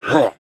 khanat-sounds-sources/_stock/sound_library/animals/ogre/ogre1.wav at main
ogre1.wav